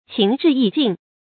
情至意盡 注音： ㄑㄧㄥˊ ㄓㄧˋ ㄧˋ ㄐㄧㄣˋ 讀音讀法： 意思解釋： 指對人的情誼已經到極點。